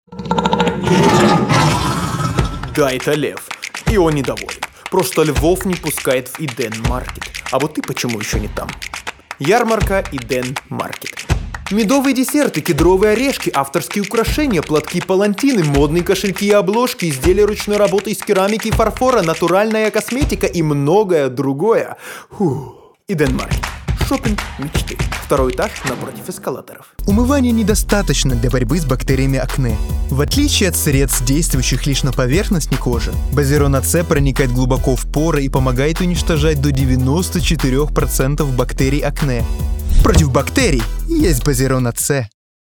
Professional voice and theatre actor.
Sprechprobe: Werbung (Muttersprache):
Russian Commercial.mp3